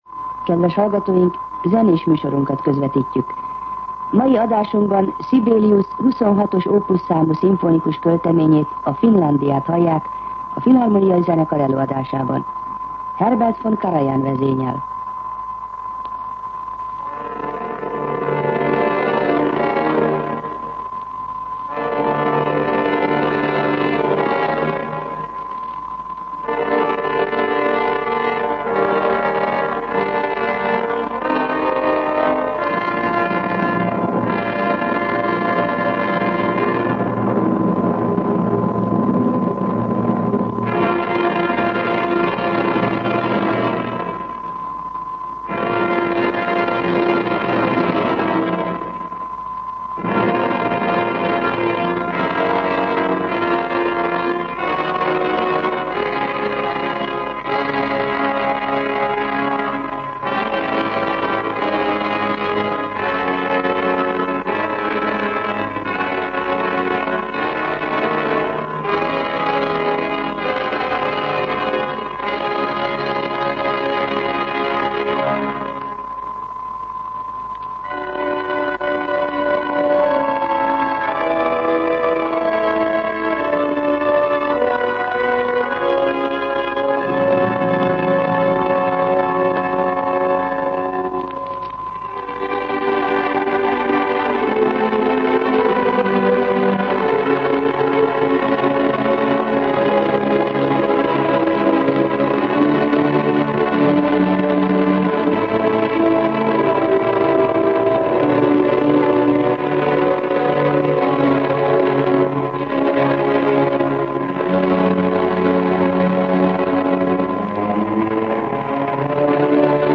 Zenés műsorunkat közvetítjük. Mai adásunkban Sibelius 26-os Opus számú szimfonikus költeményét, a Finlandiát hallják, a Filhamónia Zenekar előadásában. Herbert von Karajan vezényel.